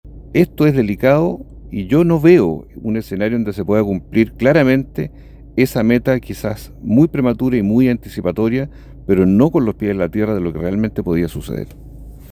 En la misma línea, el senador del PS, Juan Luis Castro, afirmó no visualizar un escenario en el que el Ejecutivo logre cumplir plenamente la meta propuesta.